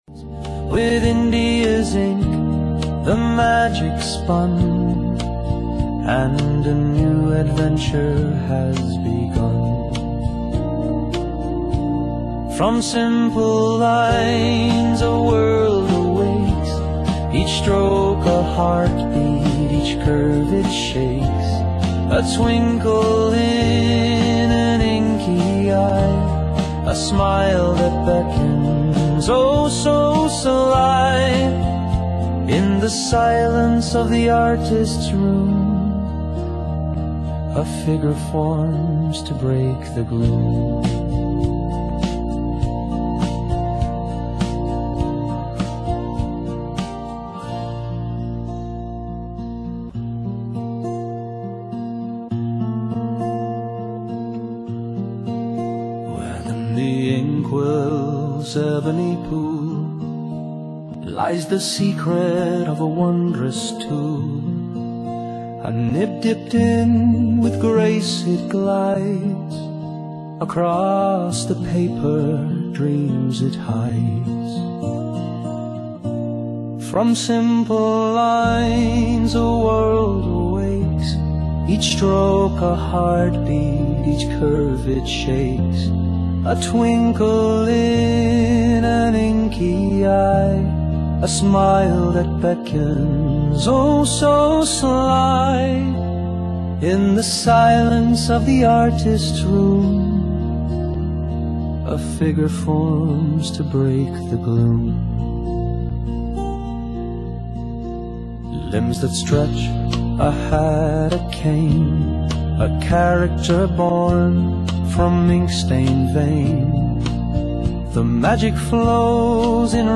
Original music composed using Suno-AI and Soundful software.
A song from the musical.
by Monkey Magic